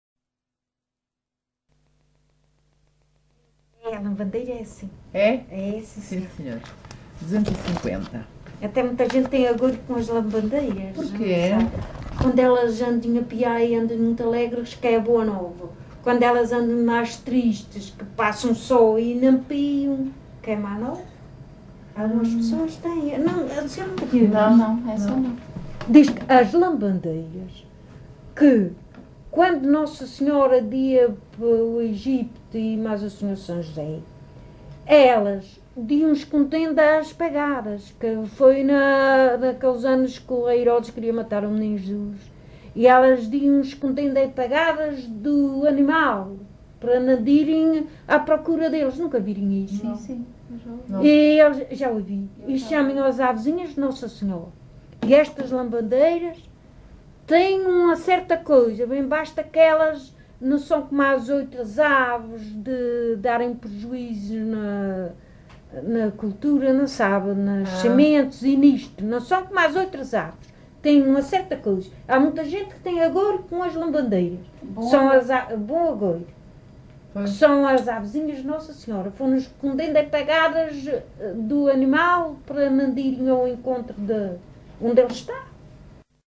LocalidadeFajãzinha (Lajes das Flores, Horta)